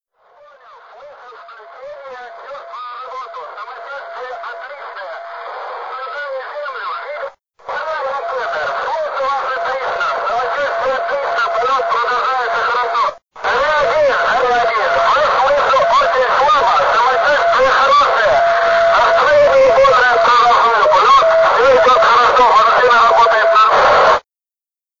Le comunicazioni con il centro di controllo furono regolari ] Alla velocità record di quasi 28 mila Km/h percorse l’orbita in 89,1 minuti e atterrò alle 10.55 presso un villaggio della regione di Saratov in un campo che ancora recava i segni del crudo inverno sovietico.
Voce_Gagarin.wav